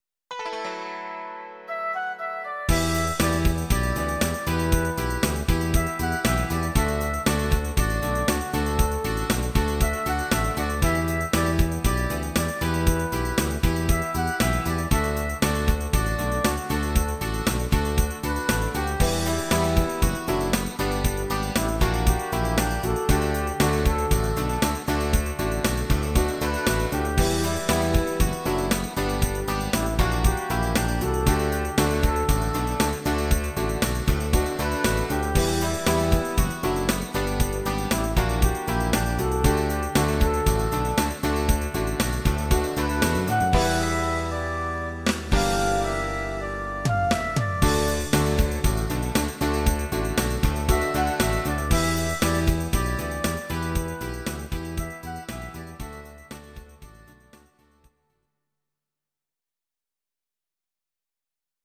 Audio Recordings based on Midi-files
Pop, Rock, 1980s